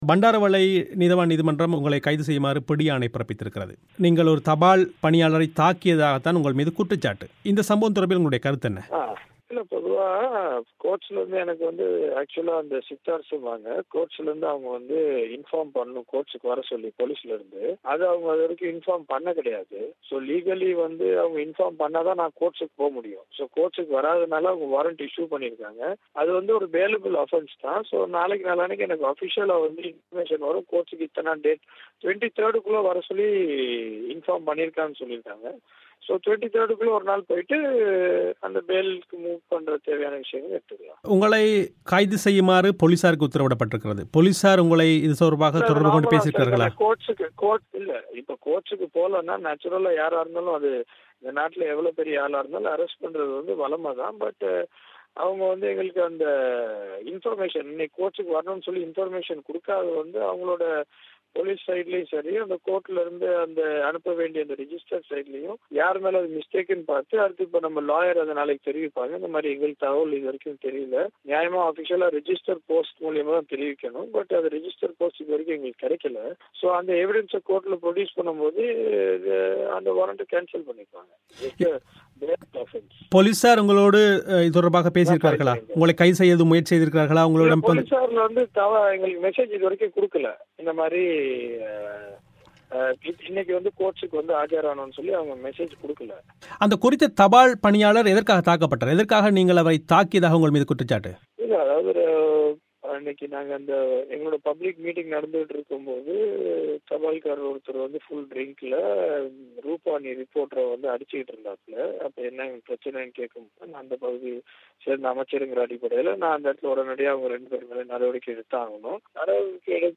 இது தொடர்பில் செந்தில் தொண்டமான் பிபிசி தமிழோசைக்கு அளித்துள்ள விளக்கத்தை நேயர்கள் இங்கு கேட்கலாம்.